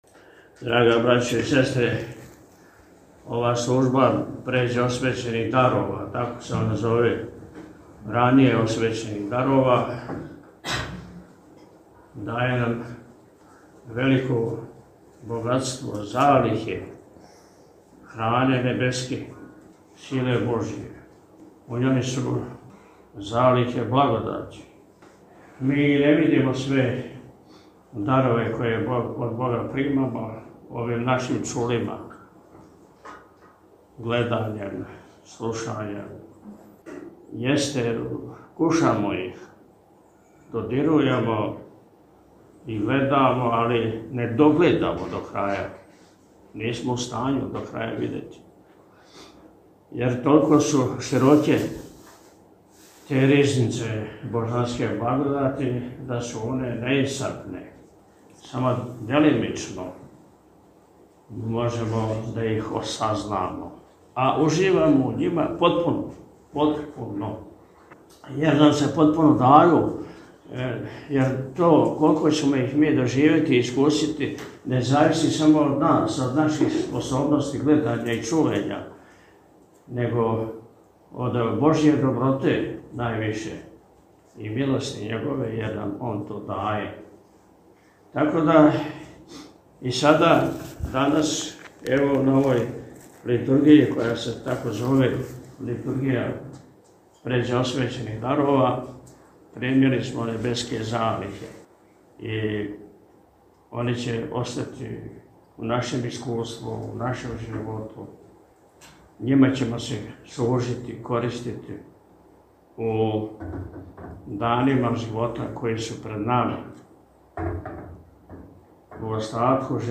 M-Sreda-Beseda.mp3